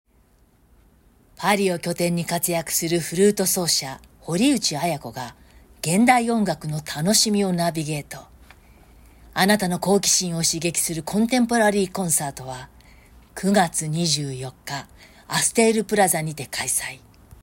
ナレーション音源サンプル　🔽
【しっとり系ナレーション】